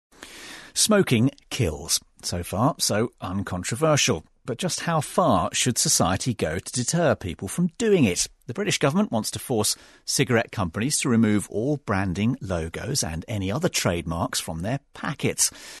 【英音模仿秀】烟草公司抗辩新规 听力文件下载—在线英语听力室